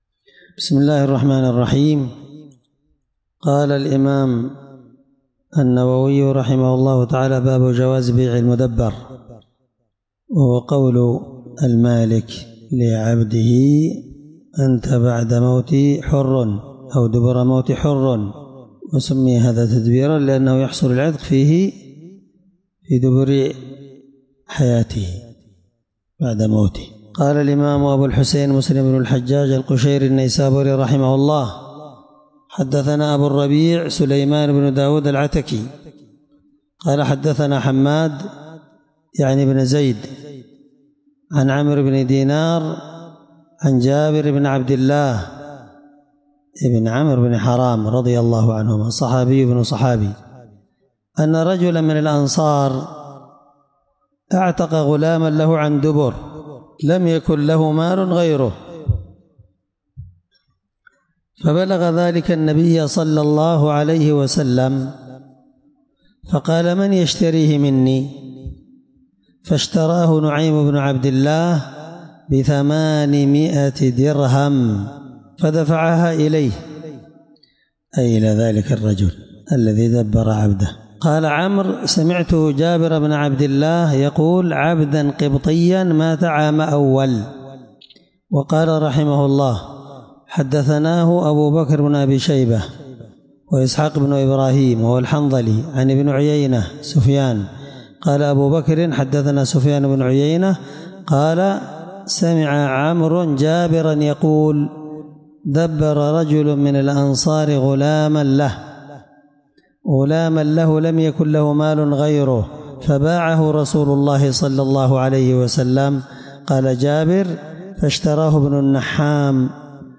الدرس17من شرح كتاب الأيمان حديث رقم(997) من صحيح مسلم